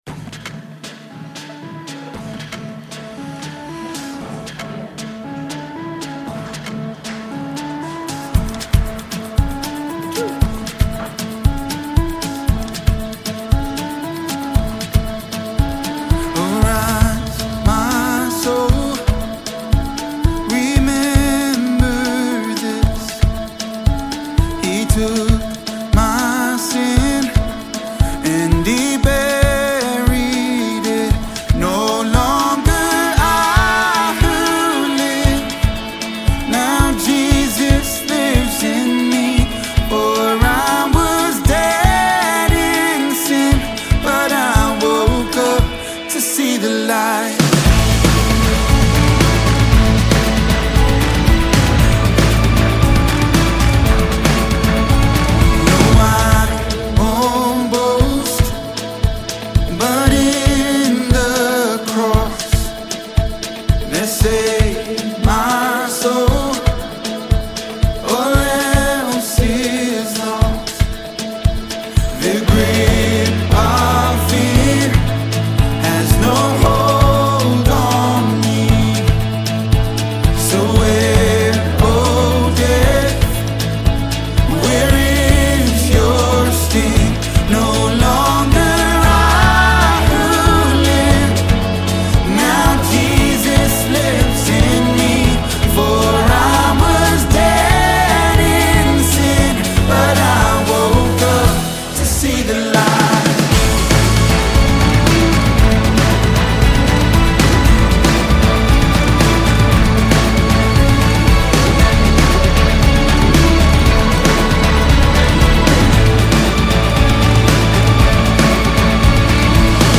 The amazing gospel music praise and worship band
live performance